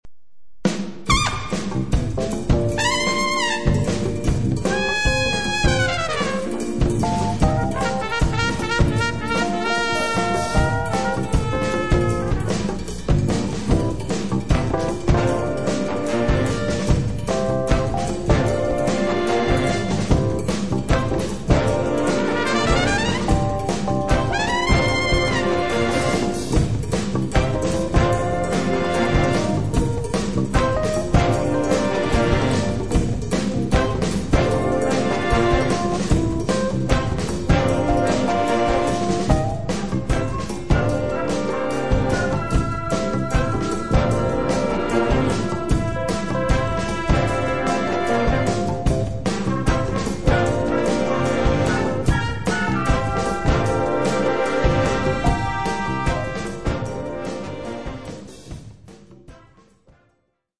piano, Fender Rhodes
tromba, flicorno
Percussioni